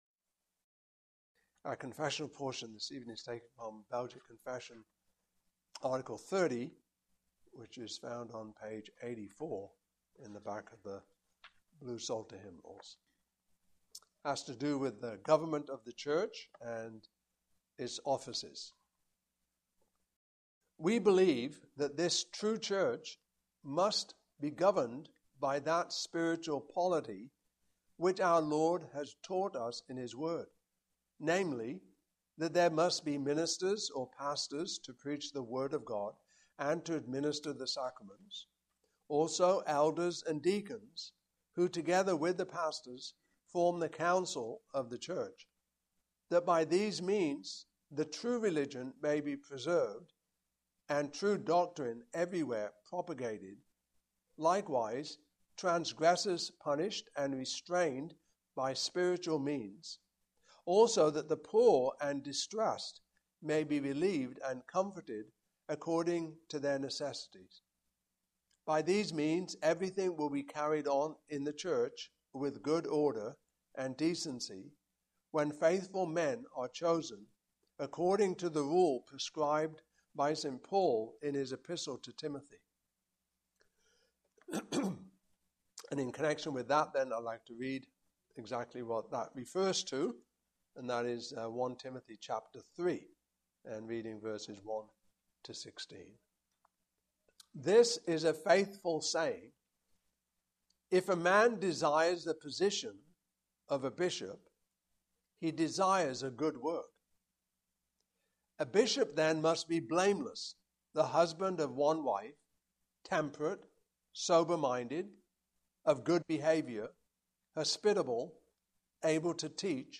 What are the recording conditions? Passage: I Timothy 3:1-16 Service Type: Evening Service